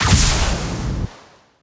Rocket Launcher
GUNArtl_Rocket Launcher Fire_03_SFRMS_SCIWPNS.wav